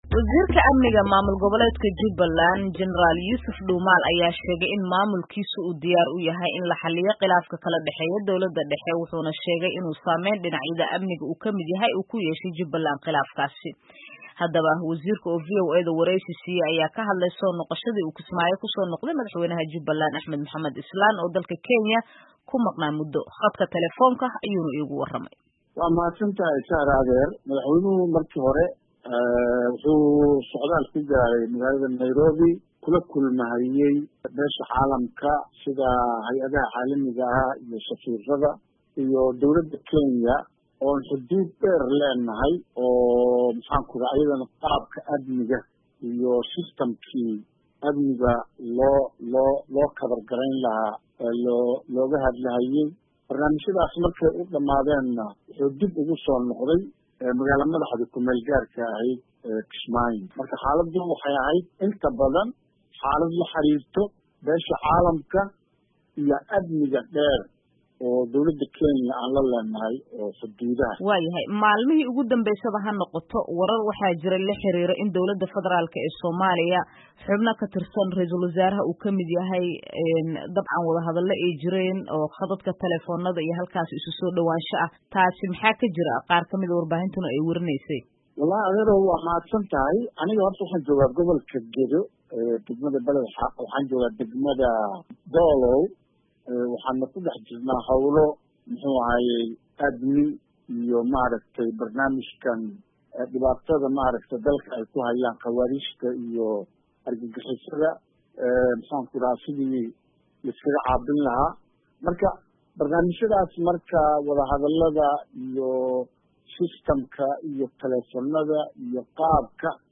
Wasiir Dhuumaal oo wareysigaan siiyey Idaacadda VOA ayaa sheegay in khilaafkaan uu saameyn weyn ku yeeshay amniga Jubbaland, isla markaana ay lama huraan tahay in la xaliyo.